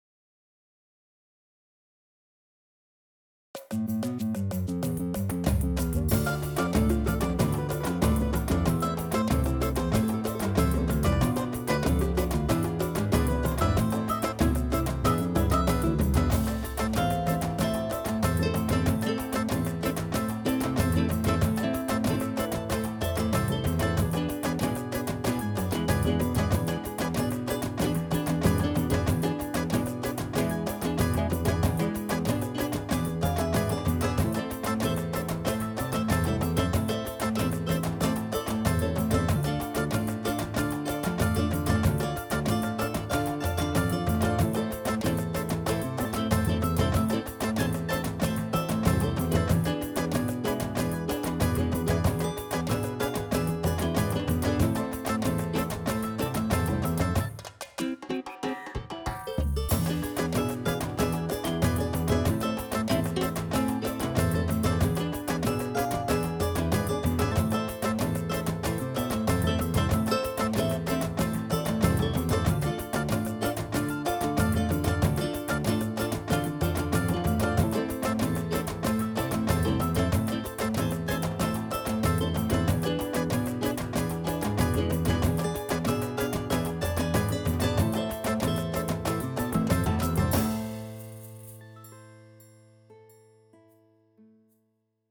As músicas foram executadas com os seguintes teclados:
MÚSICAS EXECUTADAS COM O TECLADO YAMAHA PSR-SX700